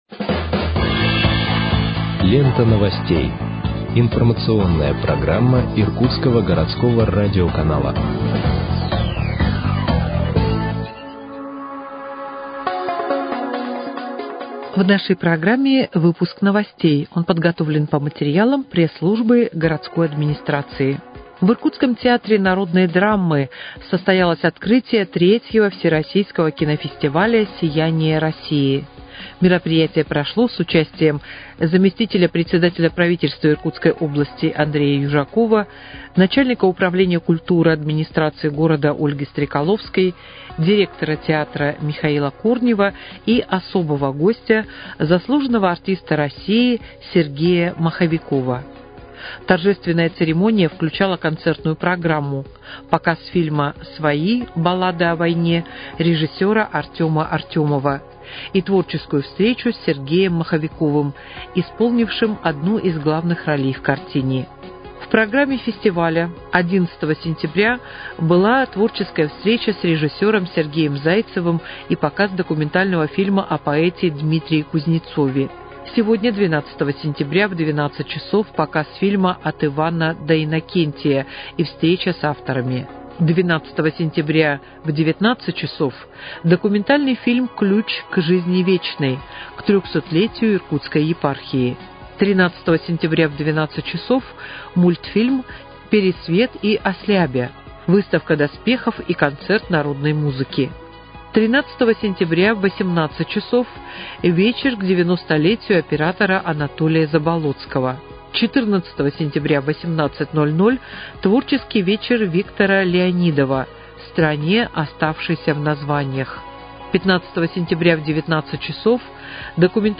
Выпуск новостей в подкастах газеты «Иркутск» от 12.09.2025 № 1